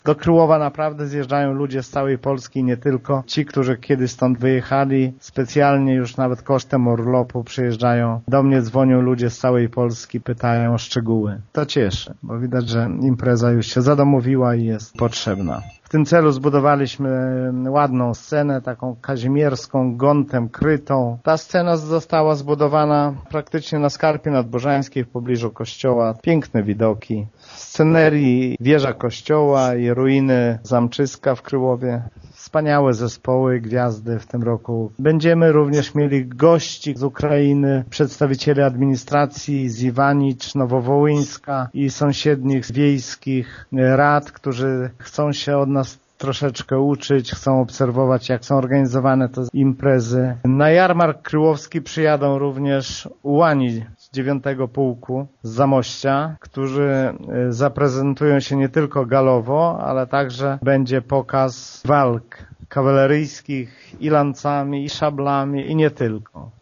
Według wójta Gminy Mircze Lecha Szopińskiego z roku na rok imprezy w Kryłowie cieszą się coraz większą popularnością: